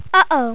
1 channel
UHOH.mp3